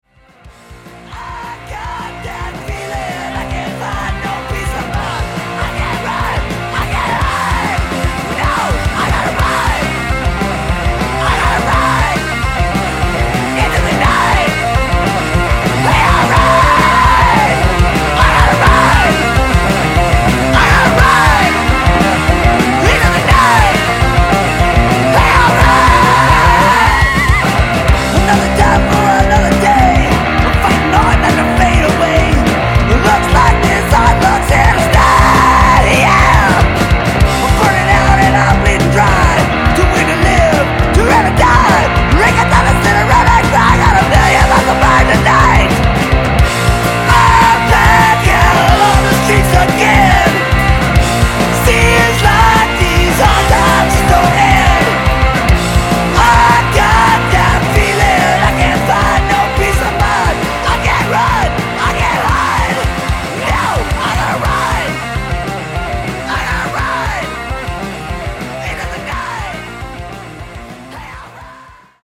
Seattle trio
unhinged shrieks